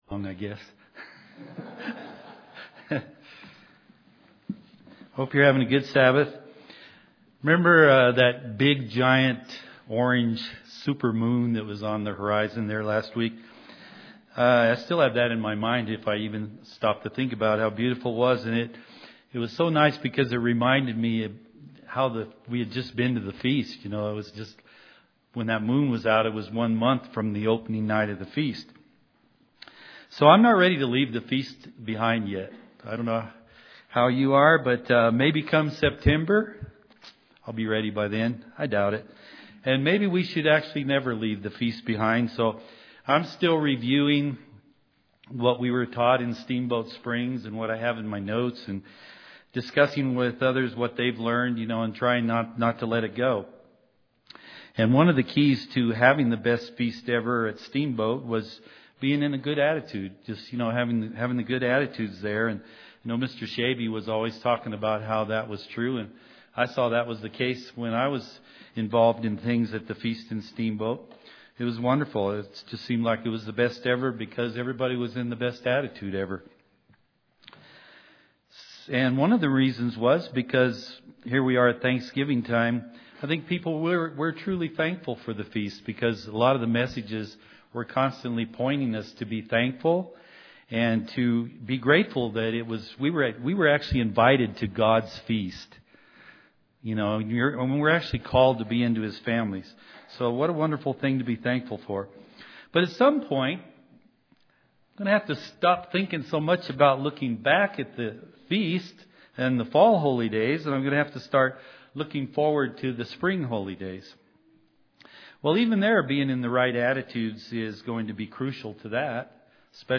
Given in Colorado Springs, CO